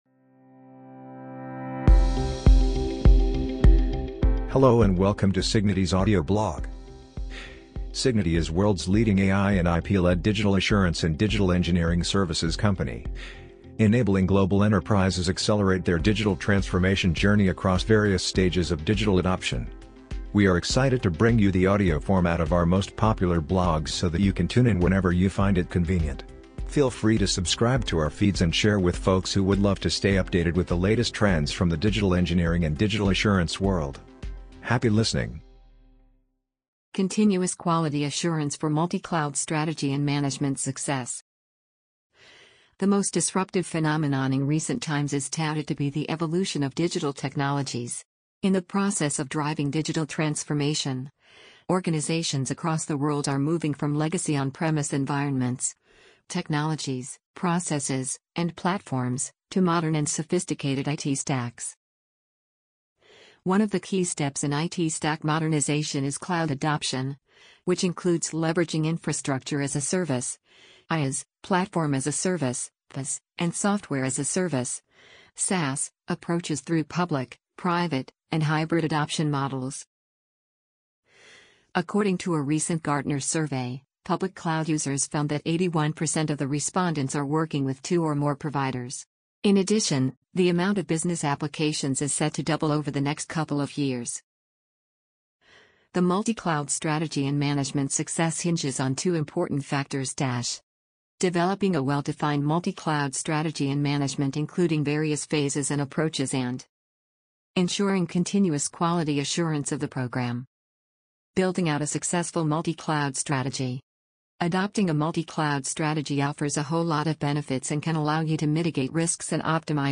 amazon_polly_15264.mp3